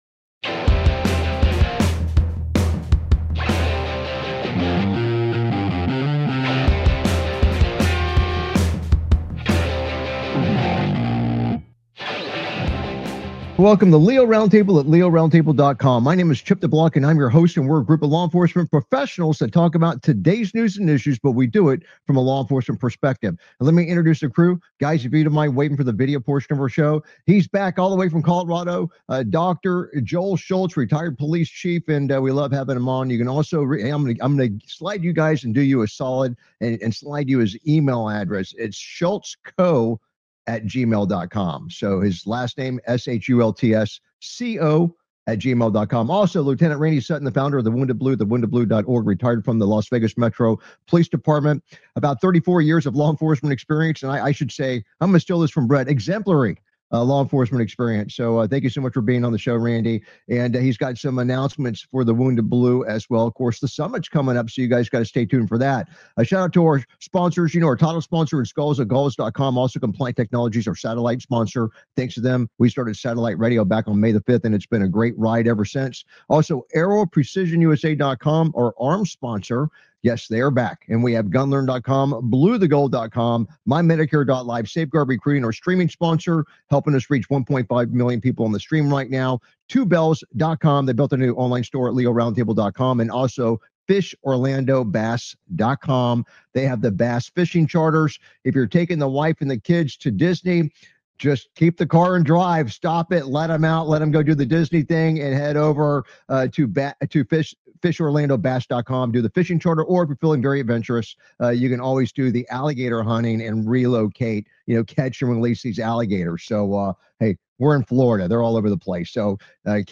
Talk Show Episode
Multiple officers fired amid sexual misconduct investigation. LEO panel discusses the federal takeover of Washington D.C..